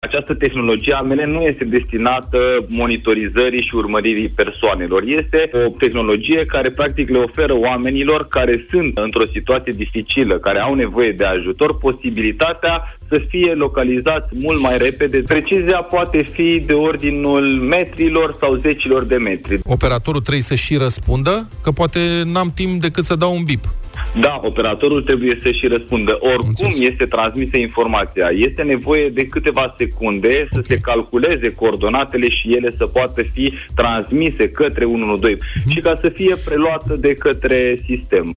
într-o intervenţie la Europa FM, în emisiunea Deşteptarea: